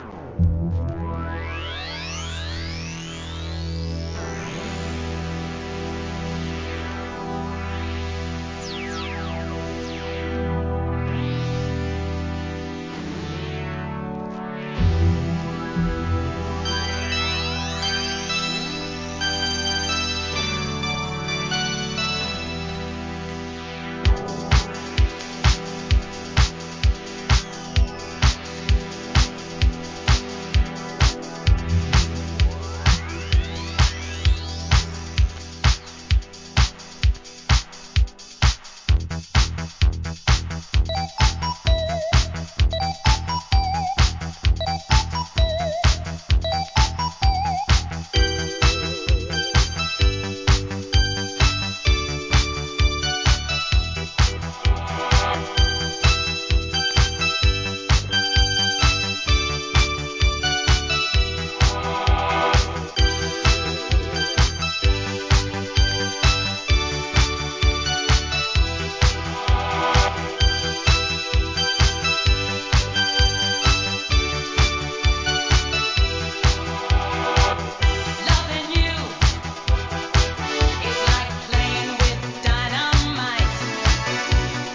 SOUL/FUNK/etc... 店舗 ただいま品切れ中です お気に入りに追加 1983年、シンセが効いたDISCO!!